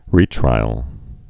(rētrīəl, -trīl, rē-trīəl, -trīl)